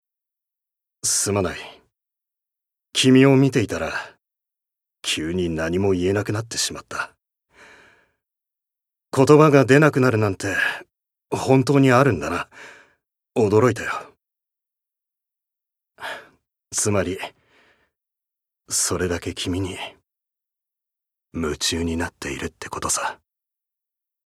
Voice Sample
ボイスサンプル
セリフ２